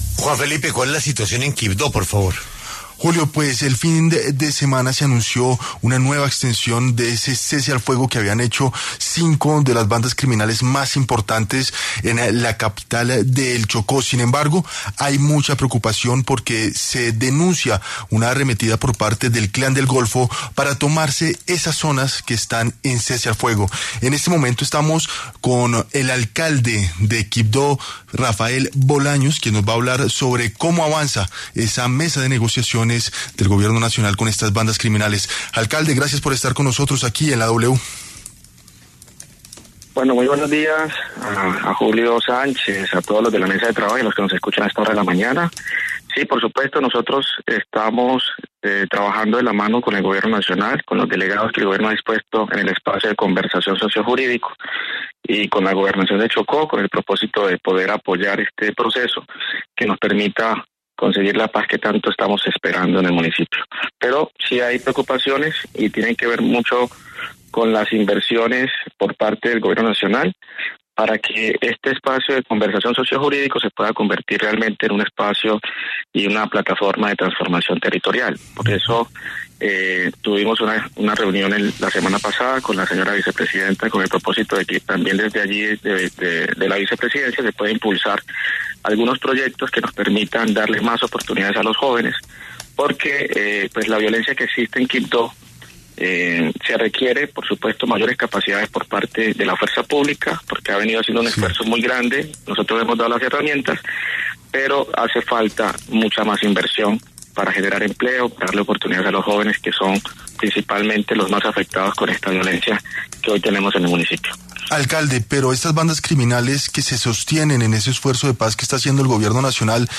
En entrevista con La W, el alcalde de Quibdó, Rafael Bolaños, explicó que este nuevo paso contribuye a mantener una relativa disminución de la violencia en la ciudad.